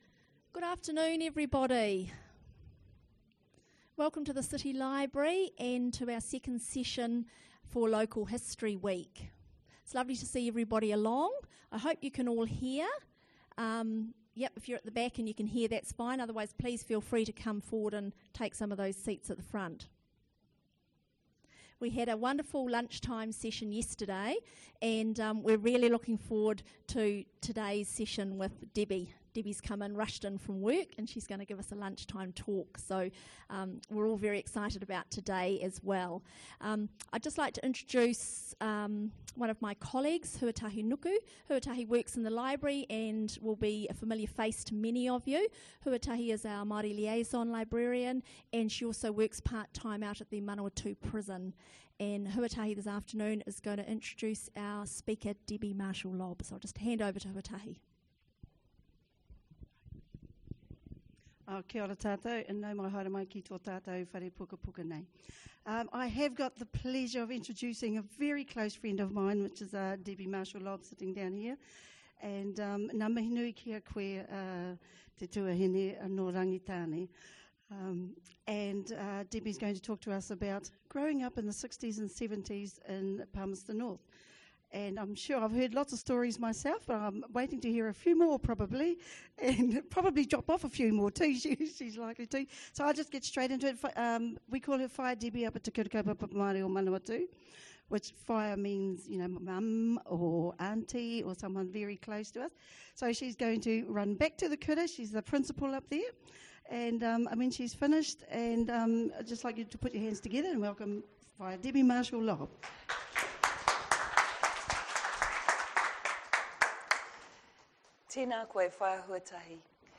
The talk was presented at the Palmerston North City Library as part of Local History Week 2008.